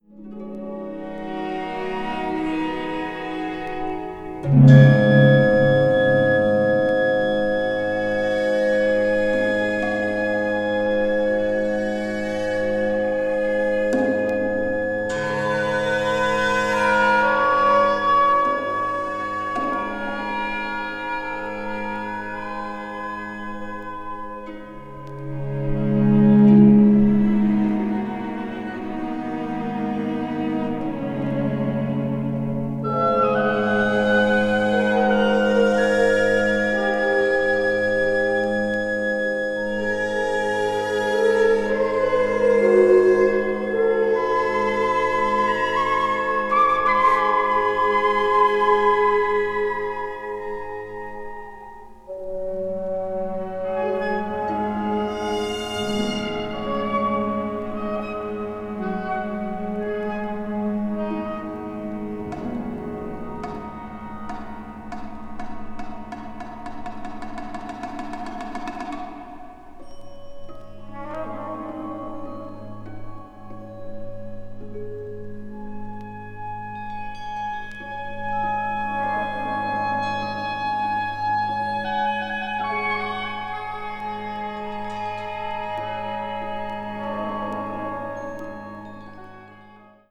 media : EX-/EX-(わずかなチリノイズ/一部軽いチリノイズが入る箇所あり)